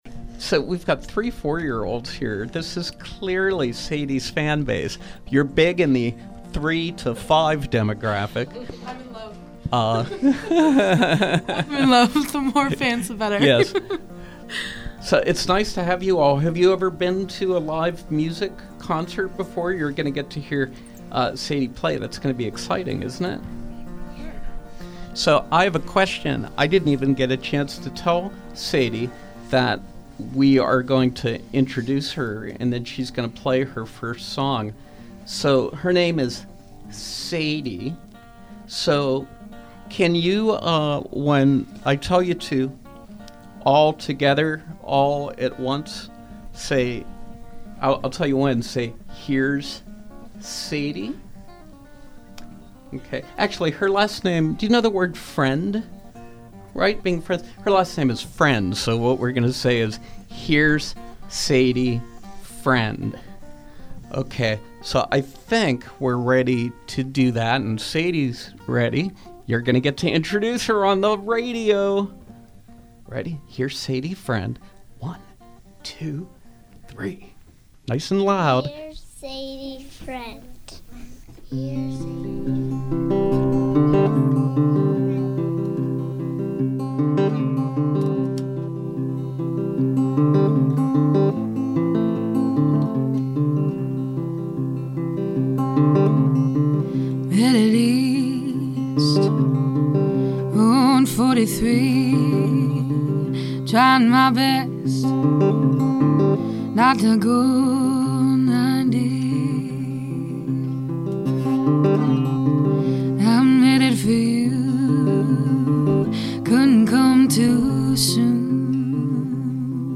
Live music with singer-songwriter